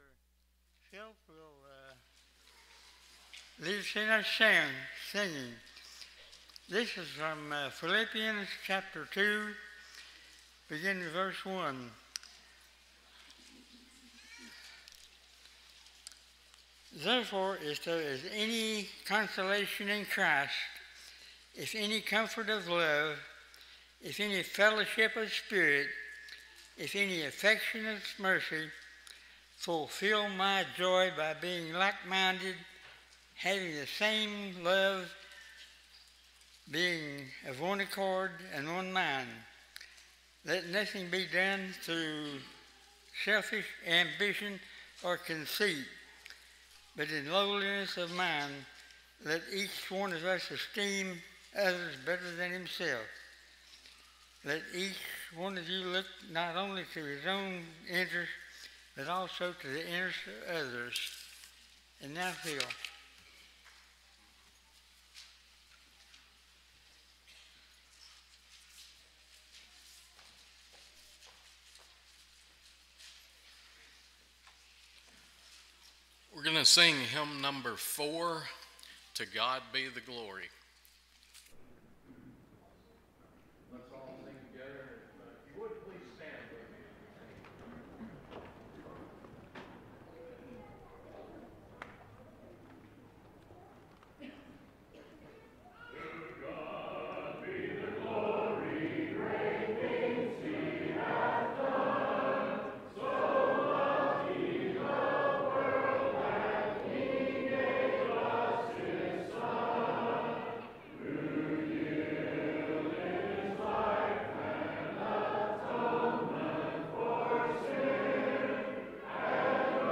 Romans 6:23, English Standard Version Series: Sunday AM Service